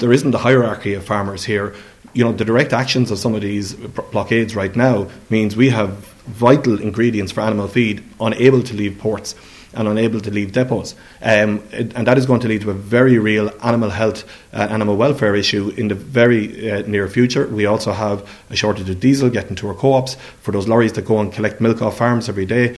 Martin Heydon says protestors should be going through representative bodies to get their concerns heard………….